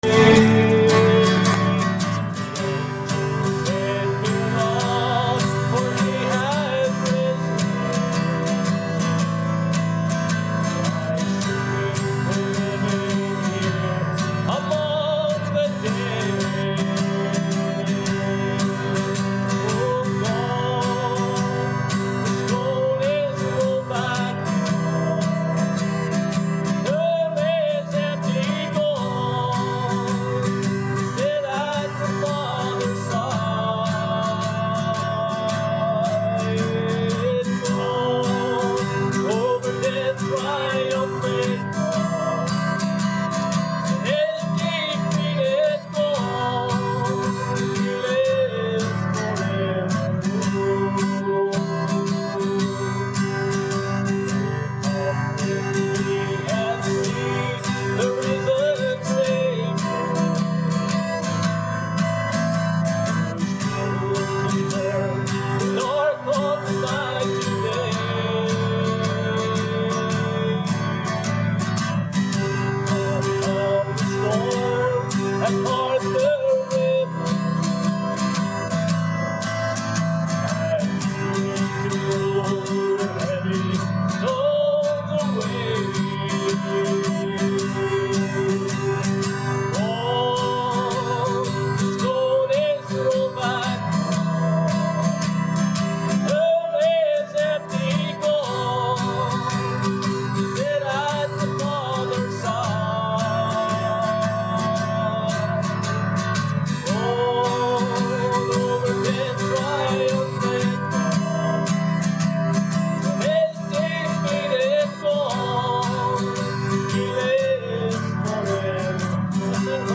Omega Ministry - Audio Sermons